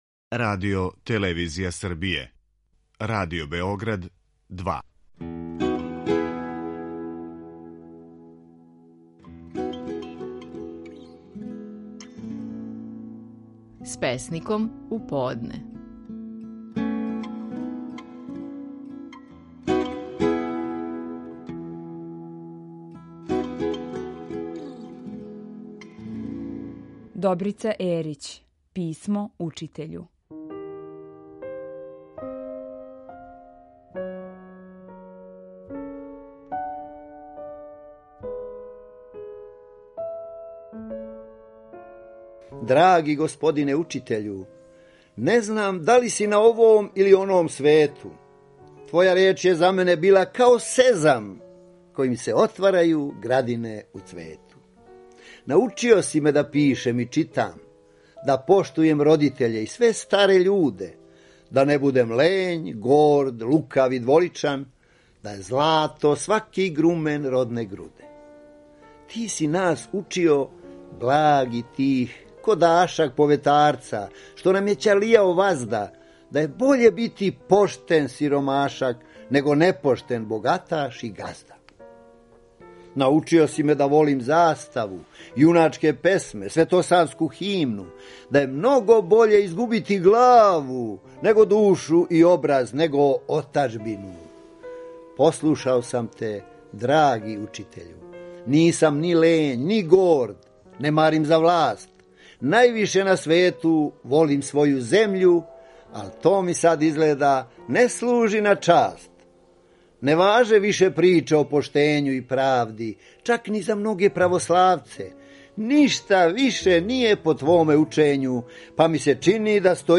Стихови наших најпознатијих песника, у интерпретацији аутора.
Можете чути како је стихове своје песме „Писмо учитељу" говорио Добрица Ерић.